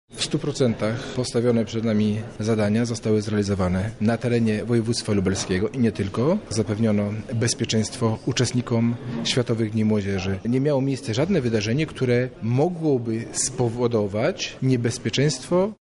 Jest to sukces organizacyjny, podkreślający profesjonalizm z jakim działały służby – mówi Robert Gmitruczuk, Wicewojewoda Lubelski